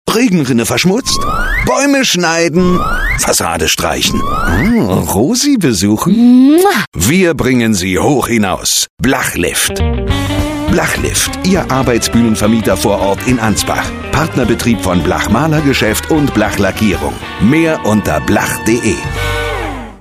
BLACHLIFT Radiowerbespots
MP3Werbespot 1 aufrufen ...